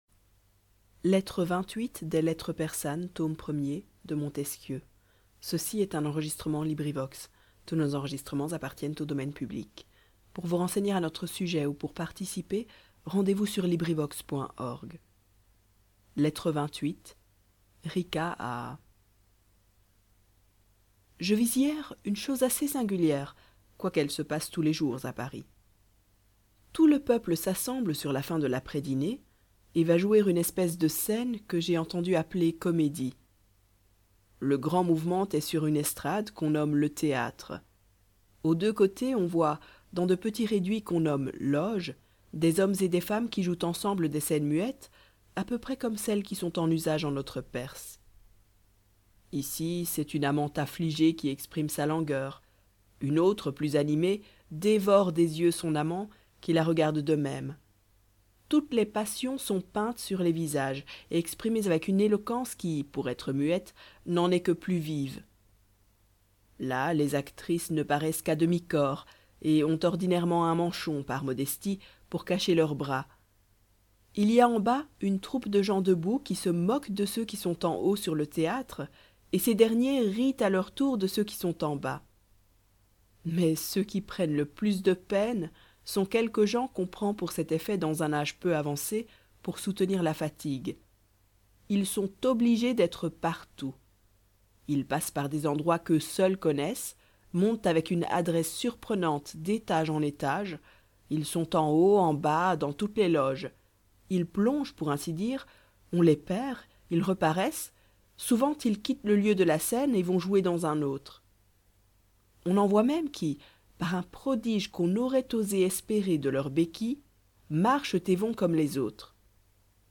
Les Lettres persanes , by Montesquieu, in French. LibriVox recording by volunteers. Lettre 28. Rica à *** .